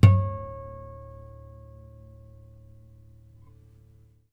strings_harmonics
harmonic-07.wav